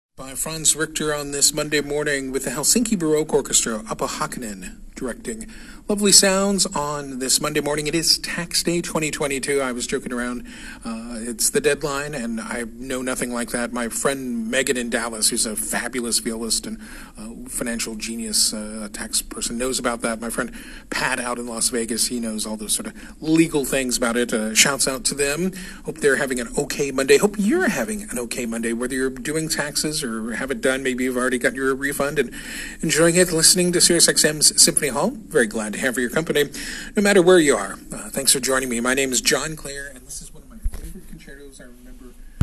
I got a shout-out on SiriusXM!
sxm-shoutout.m4a